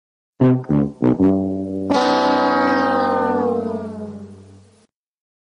Losing Horn sound effect